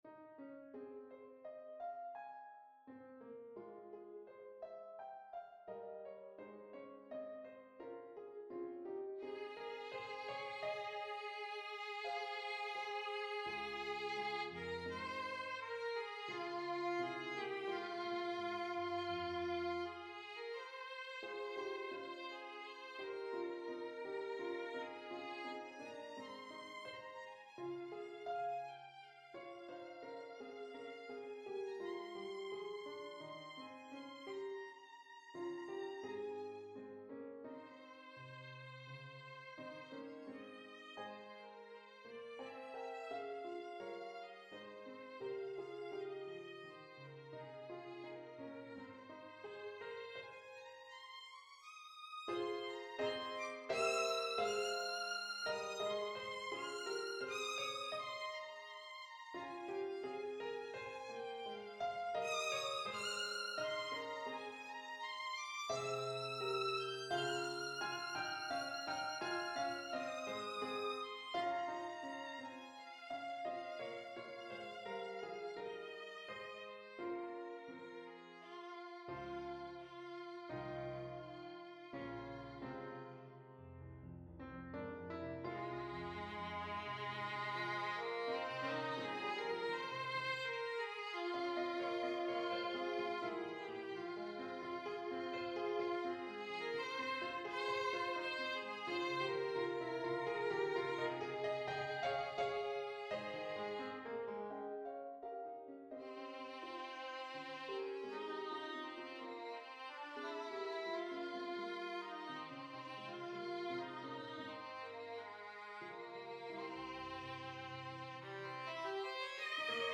Solo Violin & Piano
Note Performer 4 - mp3 Download/Play Audio